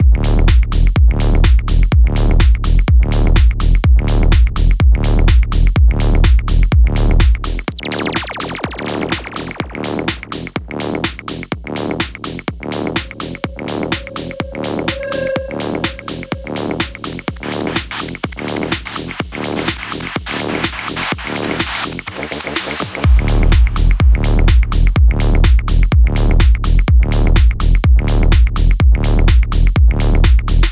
Подскажите, как накрутить такой бас